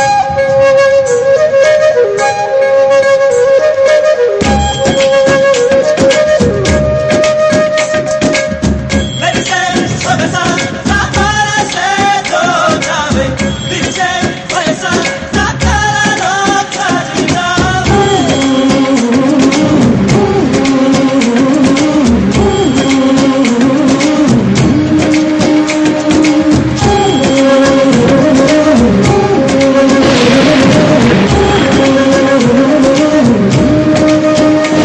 Tamil Ringtones